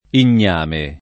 [ in’n’ # me ]